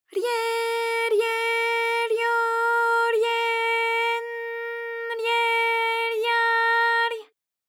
ALYS-DB-001-JPN - First Japanese UTAU vocal library of ALYS.
rye_rye_ryo_rye_n_rye_rya_ry.wav